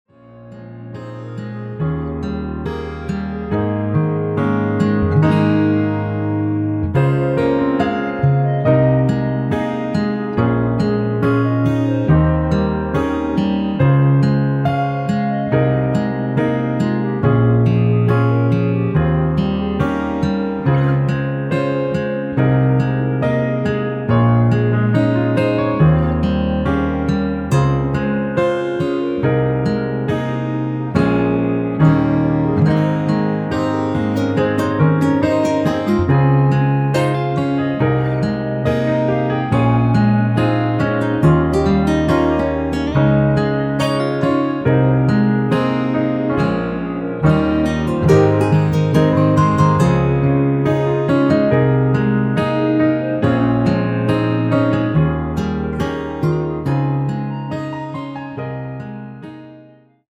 (-2) 멜로디 포함된1절후 후렴으로 진행되게 편곡 하였습니다.(아래의 가사 참조)
Ab
앞부분30초, 뒷부분30초씩 편집해서 올려 드리고 있습니다.
중간에 음이 끈어지고 다시 나오는 이유는